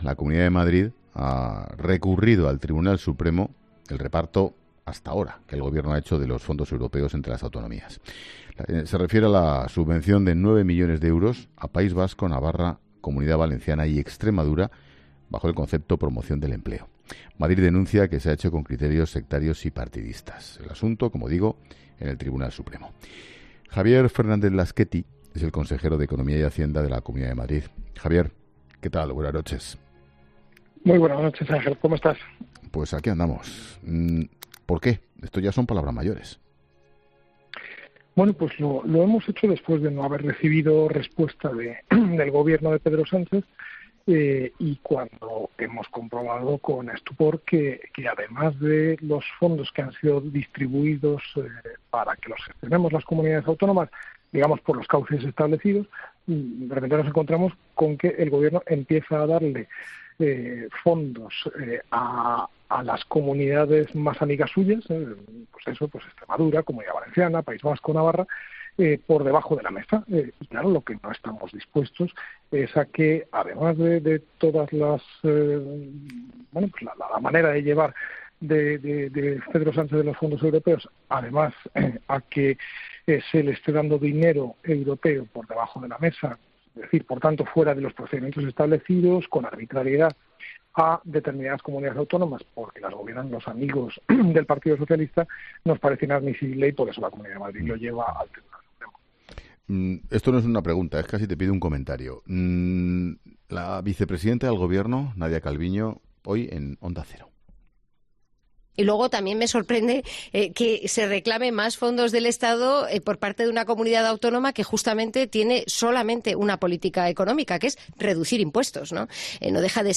En 'La Linterna' de COPE ha estado el consejero de Economía y Hacienda de la Comunidad de Madrid, Javier Fernández Lasquetty, donde ha analizado las principales claves de esta denuncia.